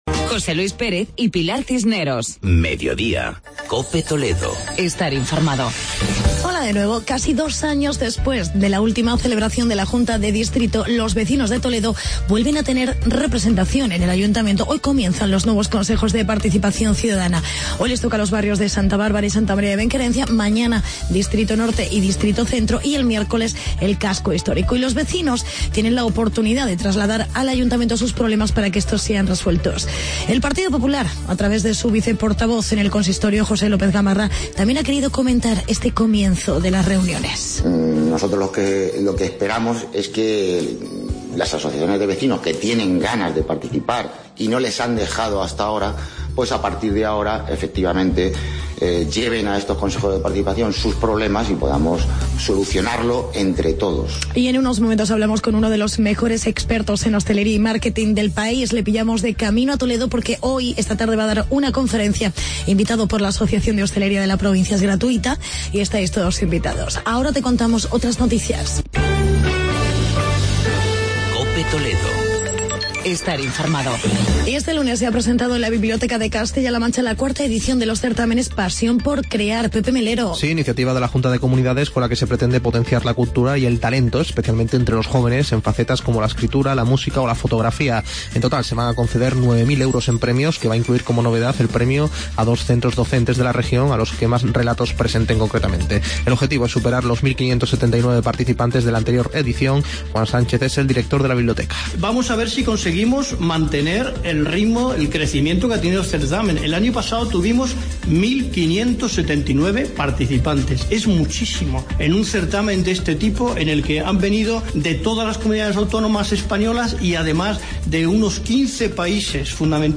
Experto en marketing y hostelería.